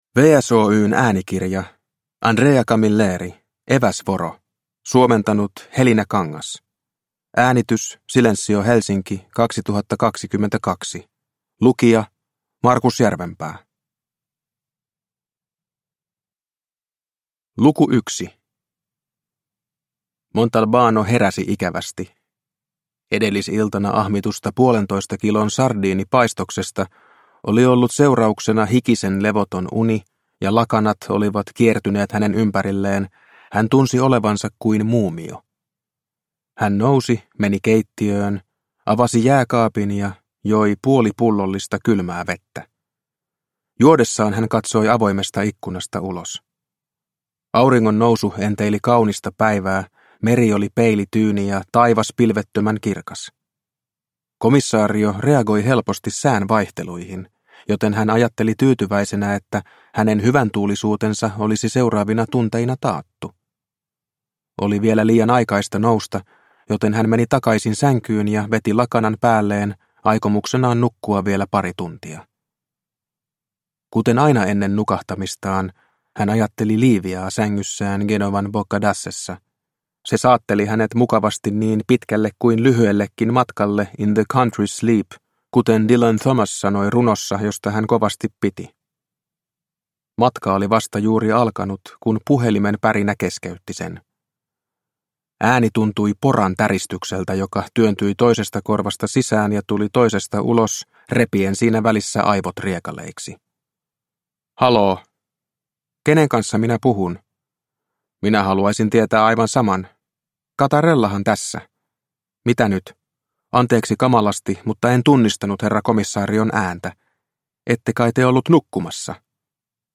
Eväsvoro – Ljudbok – Laddas ner